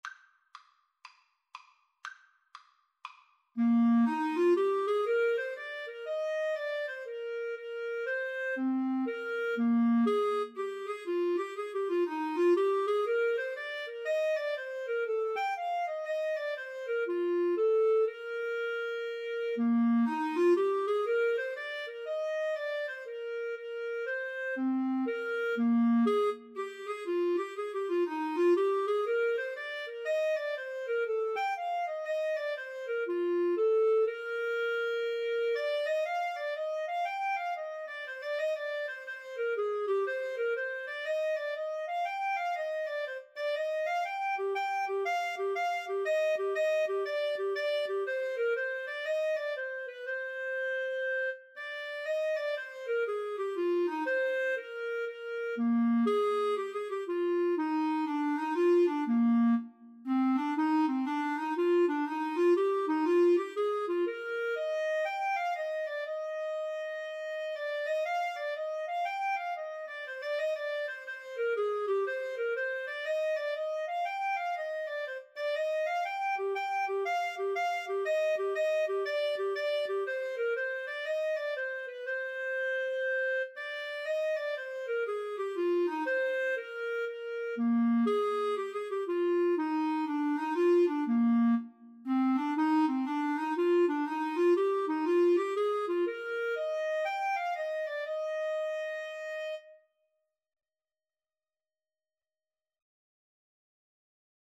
Traditional (View more Traditional Clarinet Duet Music)